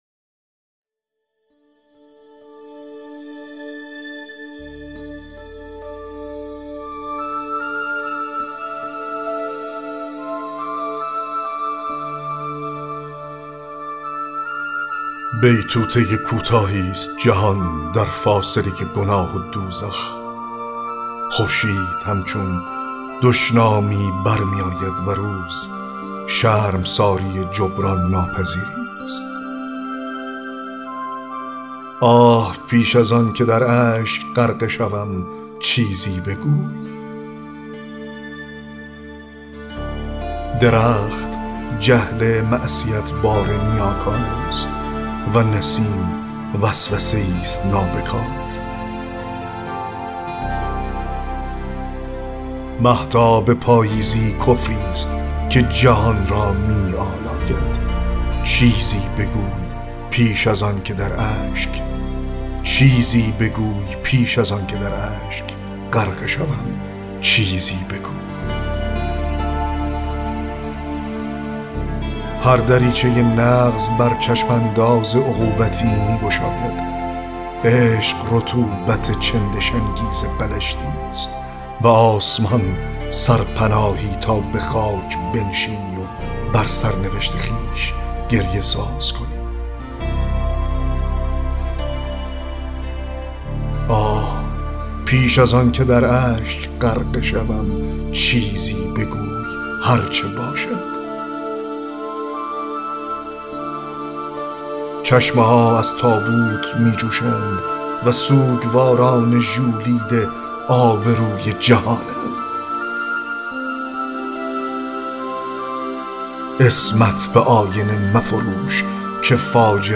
10- دکلمه شعر عاشقانه (بیتوته کوتاهی ست جهان…)